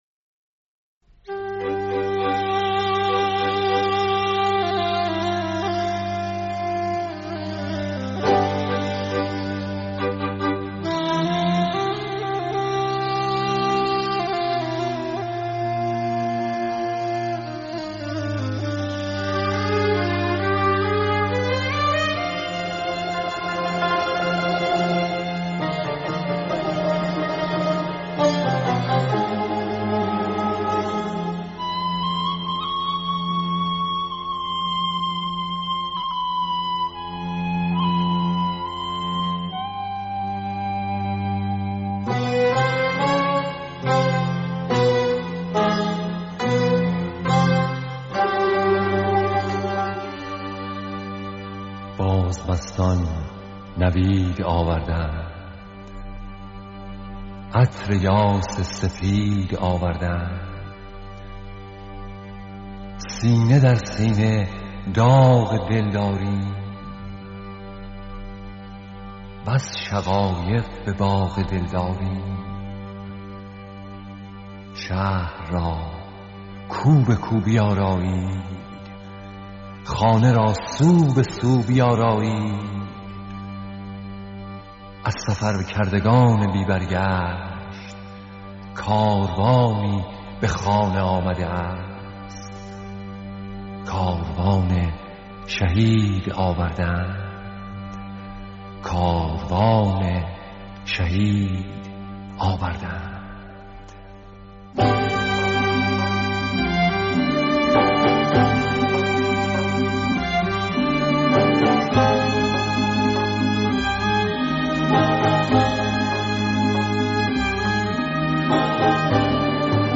تصنیف همایون